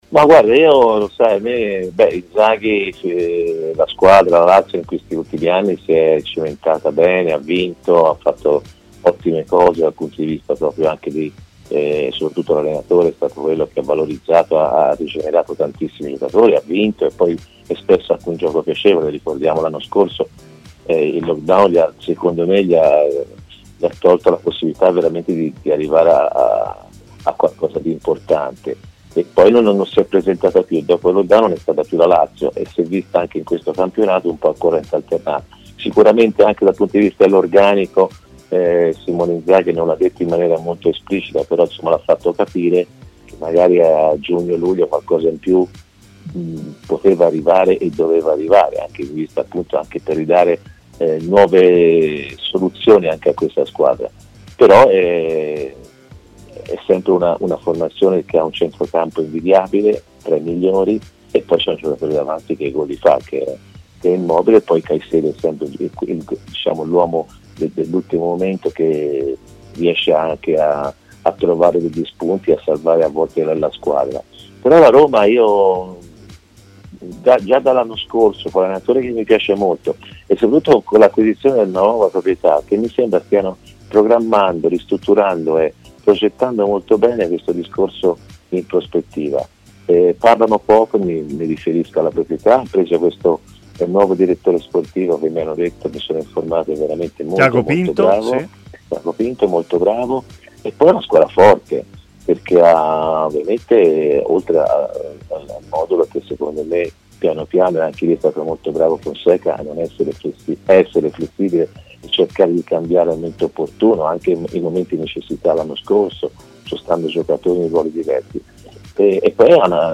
Antonio Di Gennaro è intervenuto ai microfoni di TMW Radio, esprimendosi sul derby della Capitale, in programma venerdì alle 20.45.